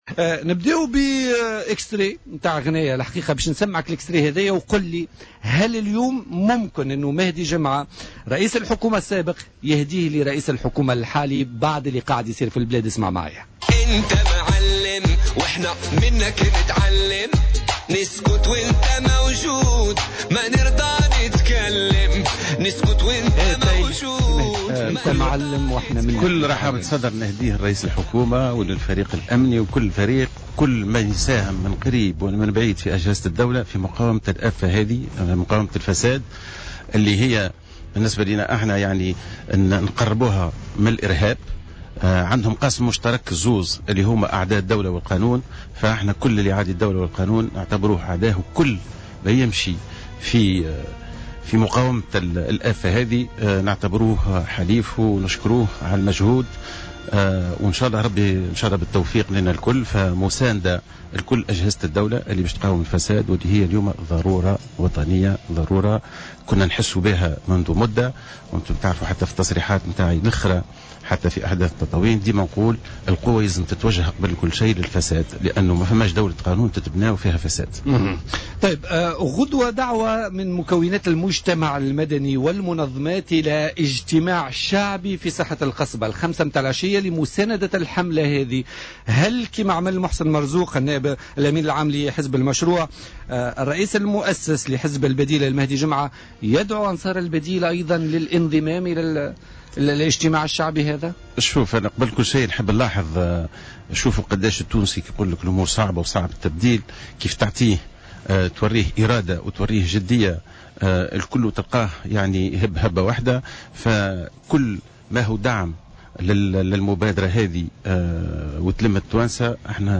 وأشاد رئيس الحكومة الأسبق، ورئيس حزب "البديل"، ضيف بوليتيكا، اليوم الخميس 25 ماي 2017، بالمجهودات التي تبذلها أجهزة الدولة في مجال مكافحة الفساد الذي لا يقل خطورة من آفة الإرهاب باعتبار وأن الظاهرتين تعاديان الدولة، مشددا على أن حكومته كانت أول من انطلق في الحرب ضد هذه الآفة.